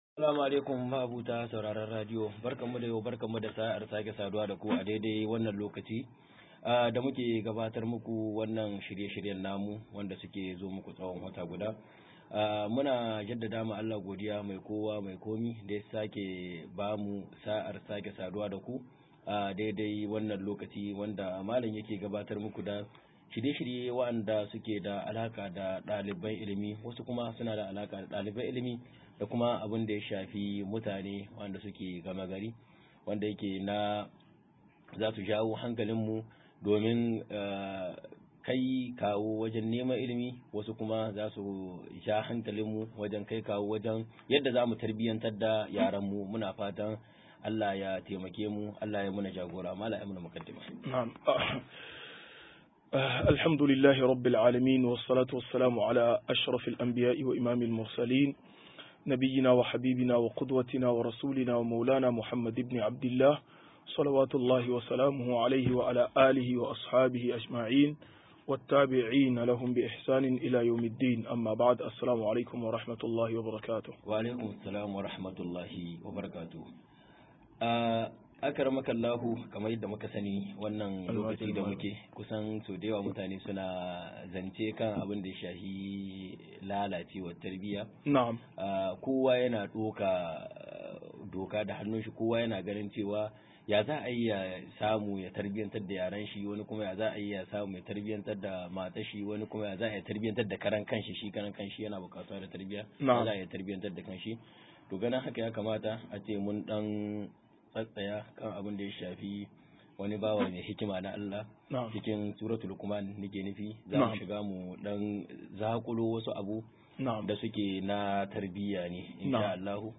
154-Fa idodin Tarbiyya a Kissar Lukuman - MUHADARA